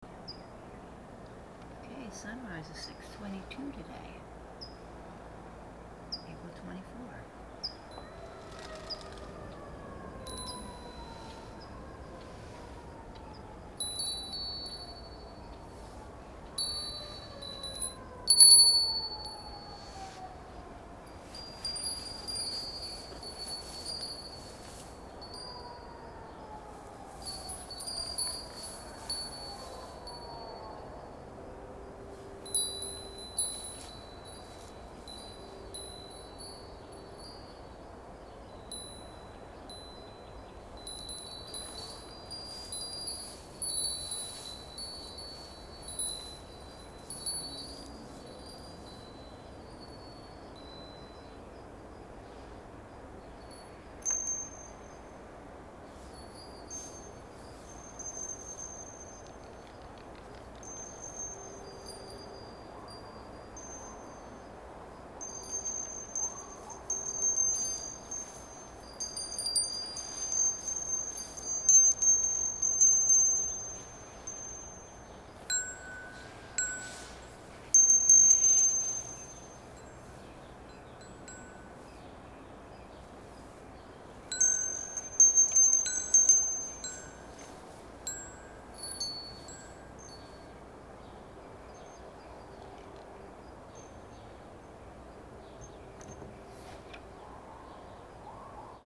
4/24 AM Sounds in the Neighborhood
The latest routine is to record audio only if I am in my yard or around my house.